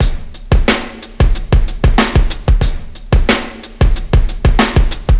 • 92 Bpm Breakbeat Sample A# Key.wav
Free drum beat - kick tuned to the A# note. Loudest frequency: 1492Hz
92-bpm-breakbeat-sample-a-sharp-key-3nm.wav